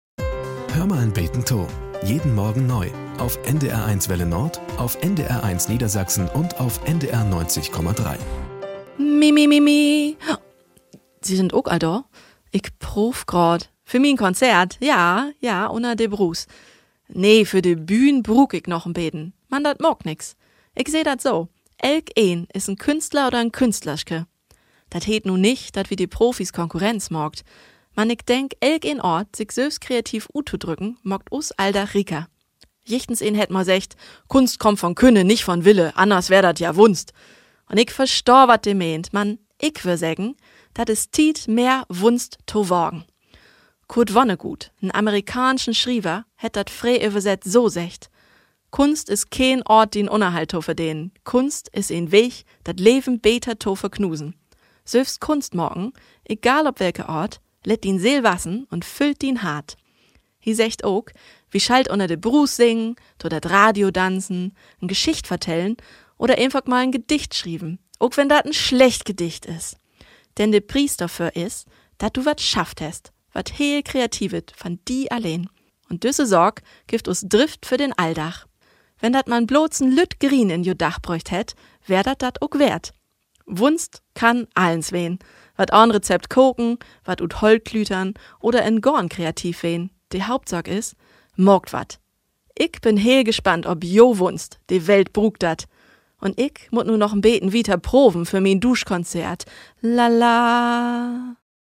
Nachrichten - 13.07.2023